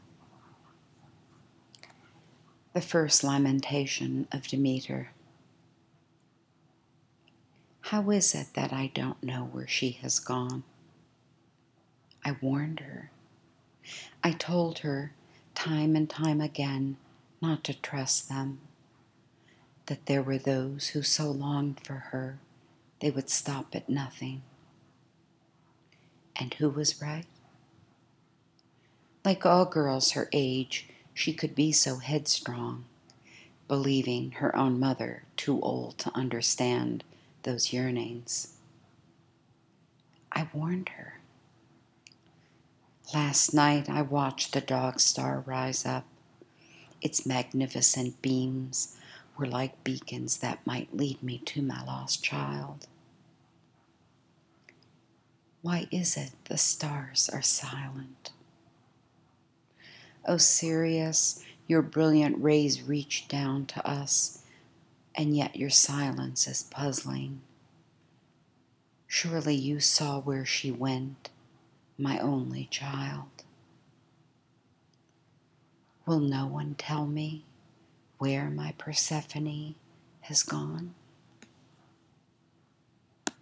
To listen to an audio recording of me reading this poem, click on the link below and wait a few seconds for it to begin:
My style of reading isn’t dramatic, and I do try hard to avoid “poet voice,” something I dislike very much. Hopefully my readings are pretty natural, maybe too natural for those who do like more drama.